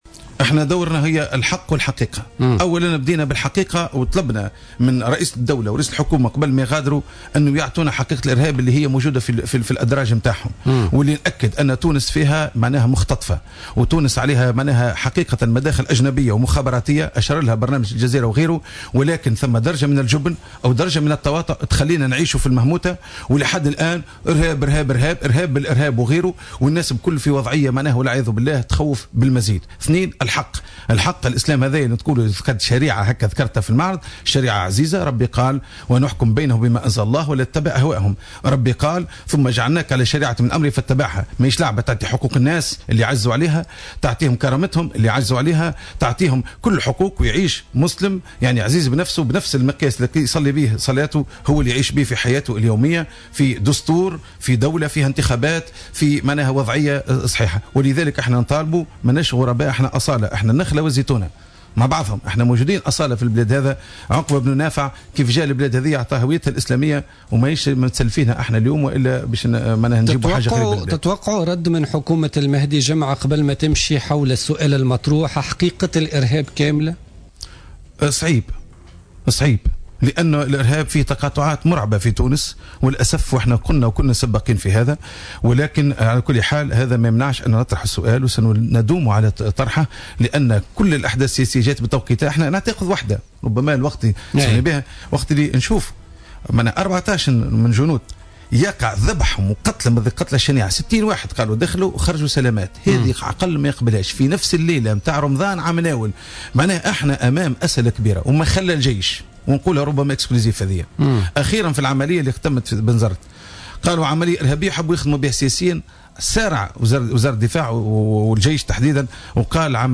lors d'une interview accordée lundi à Jawhara Fm.